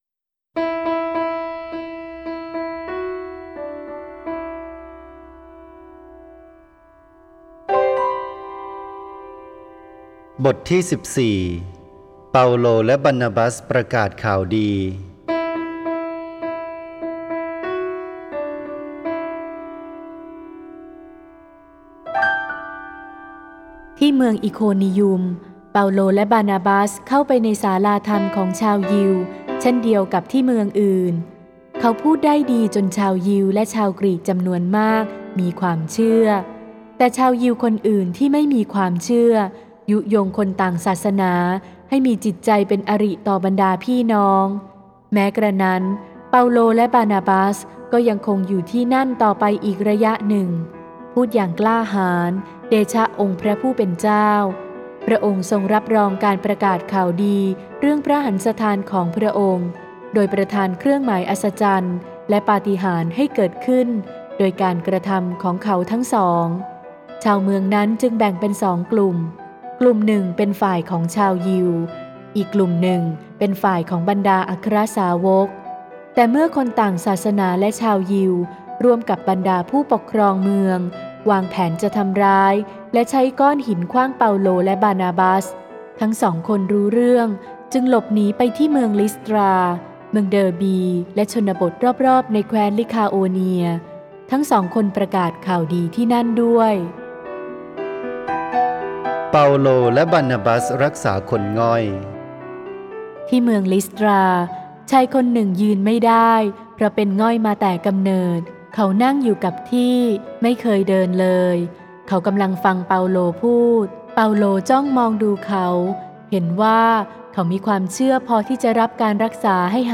(ไฟล์ "เสียงวรสาร" โดย วัดแม่พระกุหลาบทิพย์ กรุงเทพฯ)